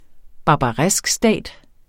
Udtale [ ˌbɑːbɑˈʁεsgˌsdæˀd ]